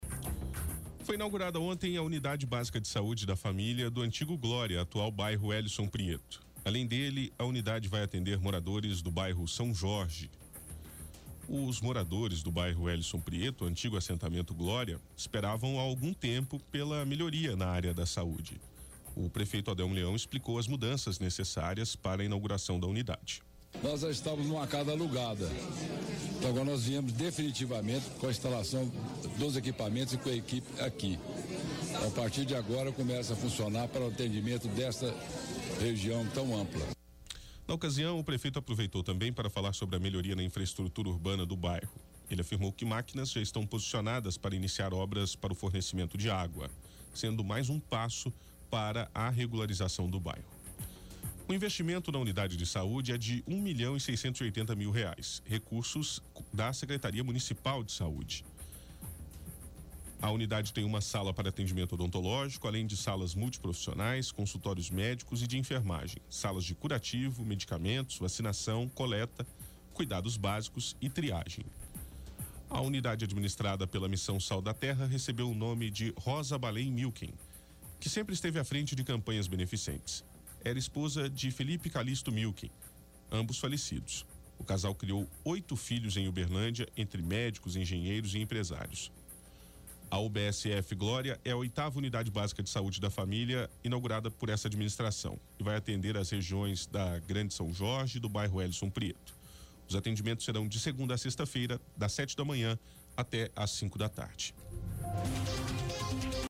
-O prefeito Odelmo explicou as mudanças necessárias para a inauguração da unidade. Aproveitou também para falar sobre a melhoria na infraestrutura urbana; afirmou que máquinas já estão posicionadas para iniciar obras para o fornecimento de água.